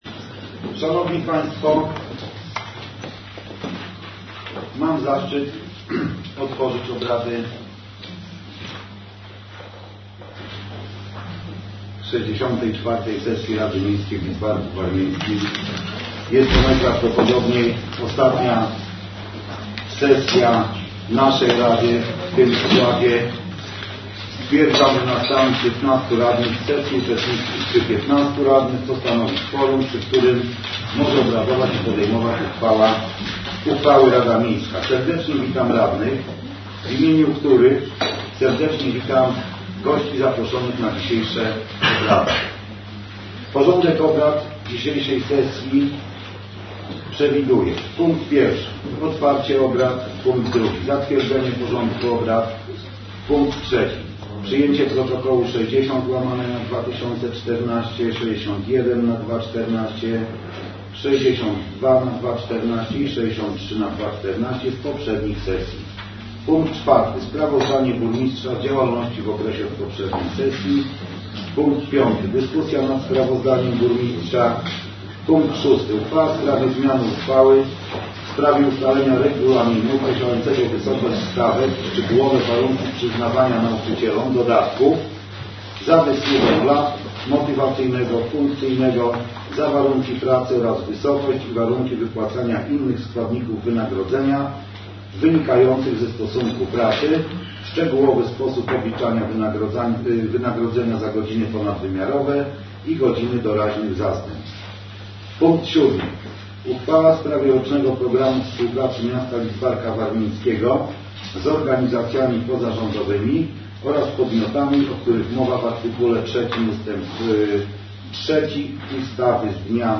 64-Sesja-Rady-Miasta-Lidzbark-Warmiński.mp3